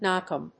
/nάnkὰm(米国英語), nˈɔnk`ɔm(英国英語)/